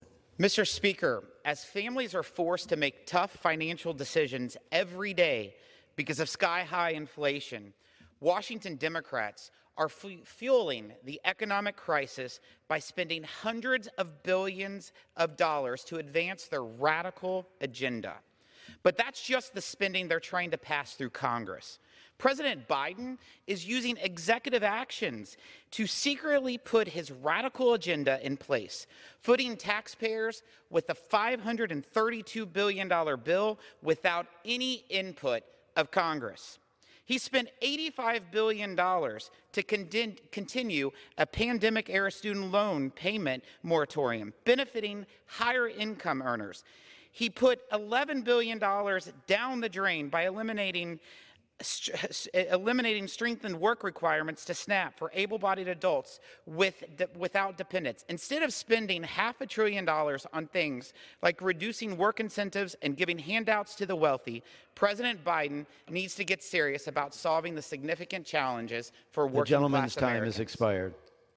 WASHINGTON – Yesterday, U.S. Representative Jason Smith (Mo.) spoke on the House floor to slam President Biden for using executive actions to secretly put his radical agenda in place, footing taxpayers with a $532 billion bill with the stroke of a pen and without any input from Congress.